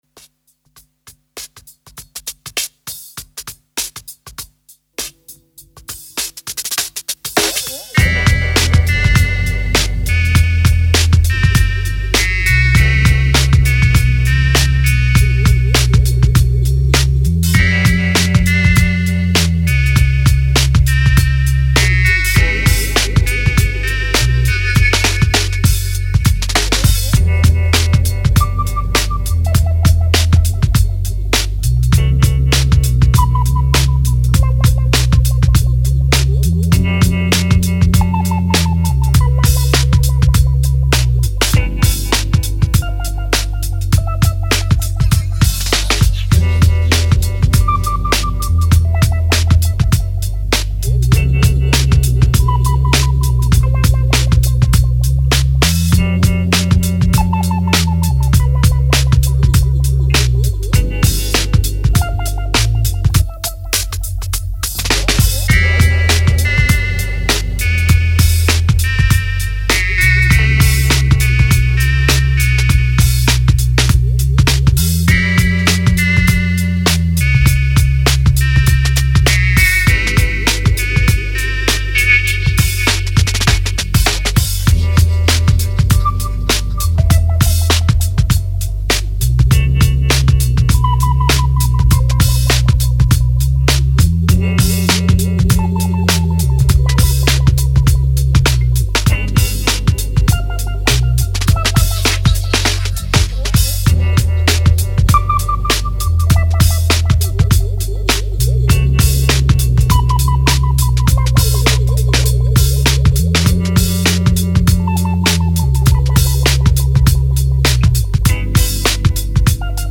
Twisted hip hop beat with an odd experimental flavouring.